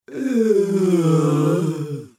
男性
青年ボイス～ホラー系ボイス～
【ゾンビ声2】